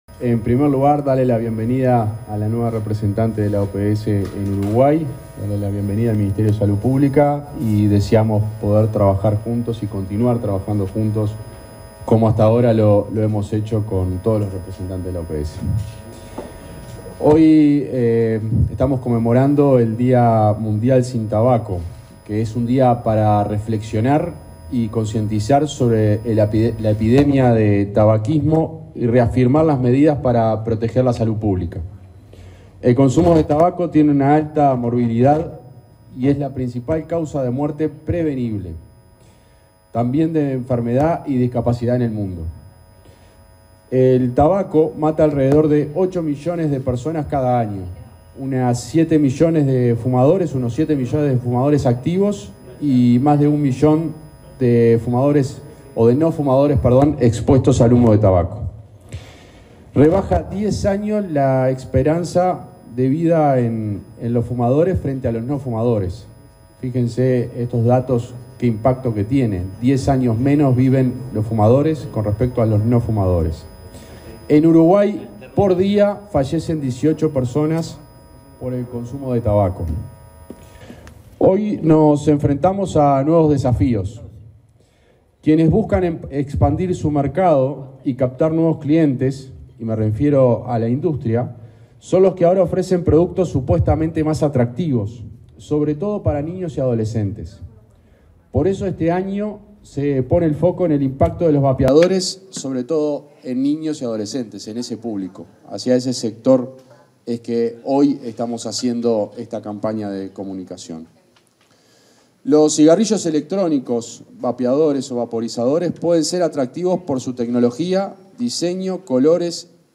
Acto oficial en el Ministerio de Salud Pública por el Día Mundial sin Tabaco
acto tabaco.mp3